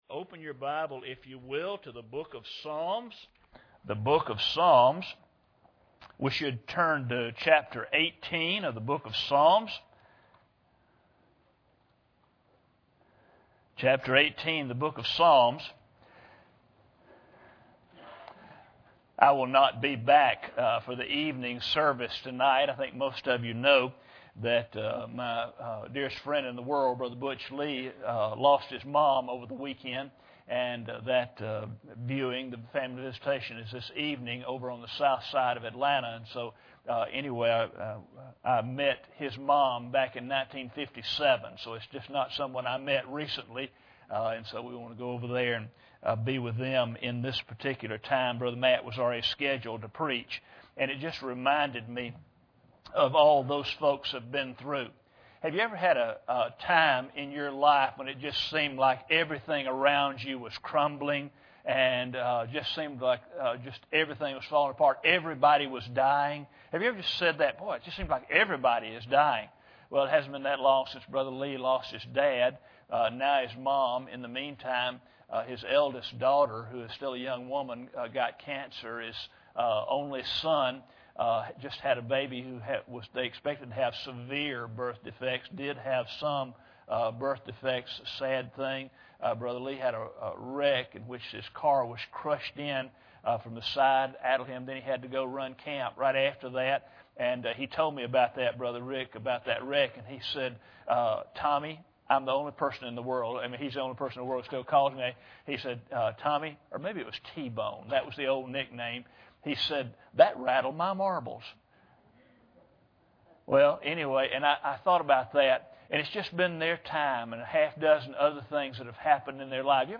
Passage: Psalm 18:2 Service Type: Sunday Morning